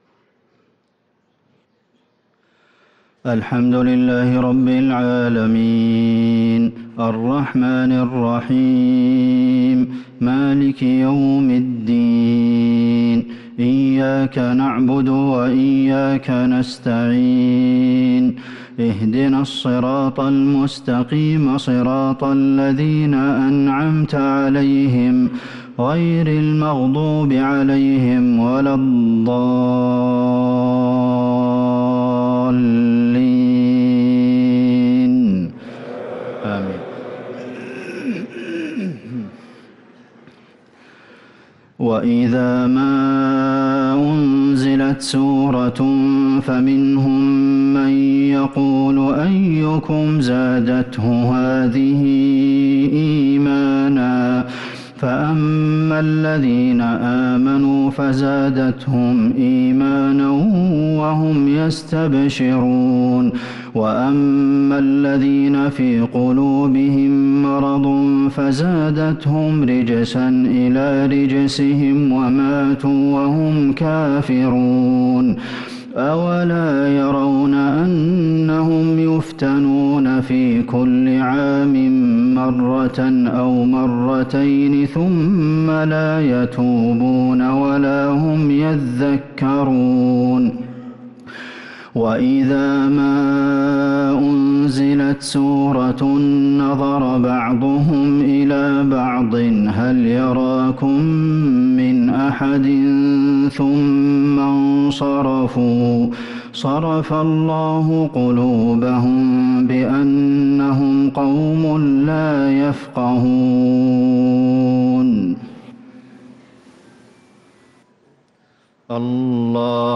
صلاة المغرب للقارئ عبدالمحسن القاسم 22 ربيع الآخر 1444 هـ